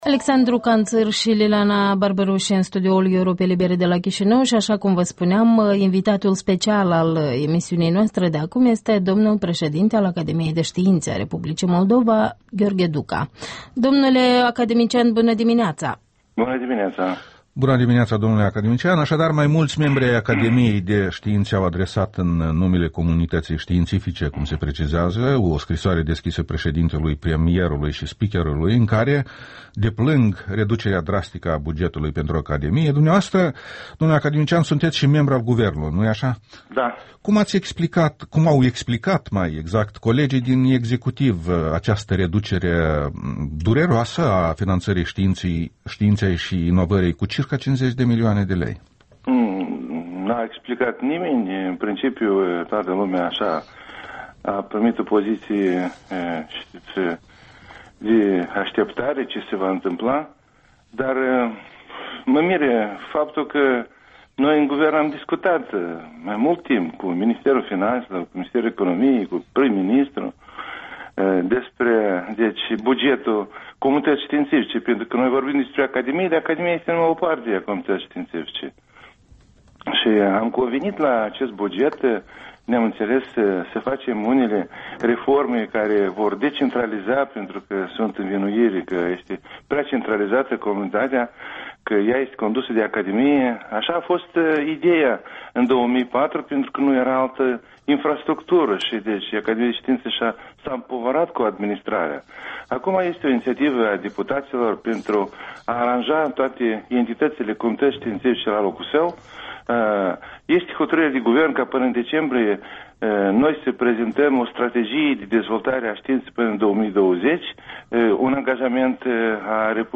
Interviul matinal la Europa Liberă cu Gheorghe Duca